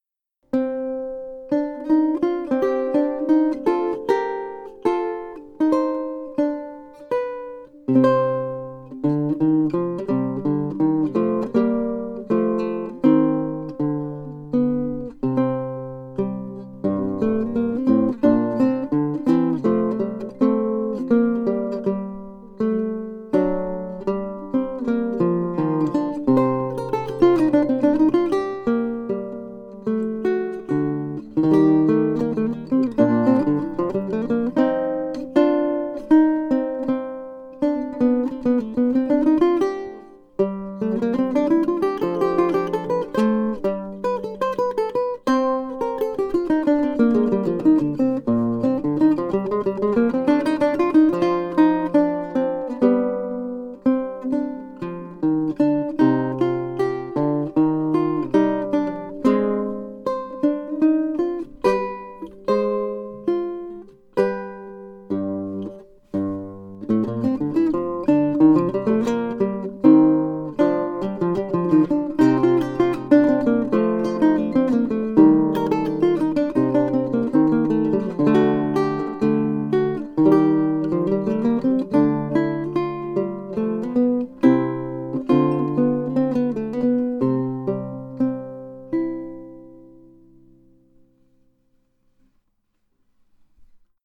ビウエラ
表も裏もサイドも全部屋久杉で、ネックはマホガニー、指板はパープルハート。
vihuela_mudarra_fantasia1.mp3